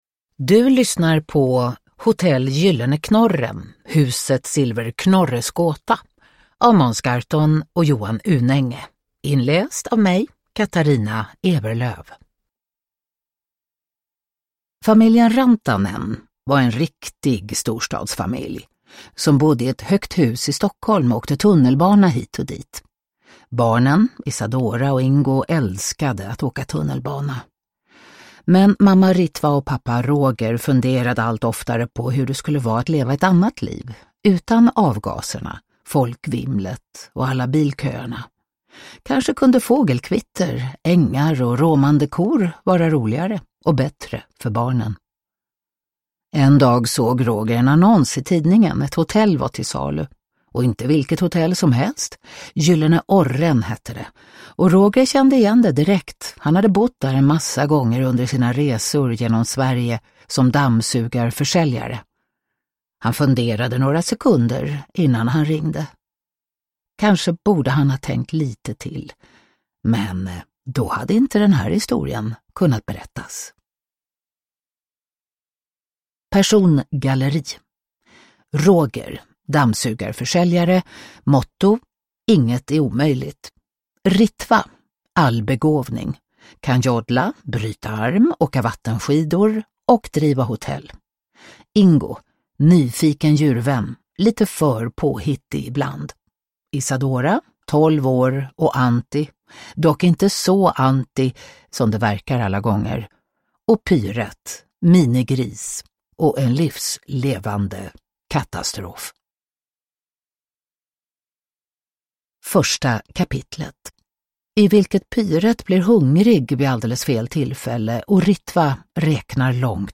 Huset Silverknorres gåta – Ljudbok – Laddas ner
Uppläsare: Katarina Ewerlöf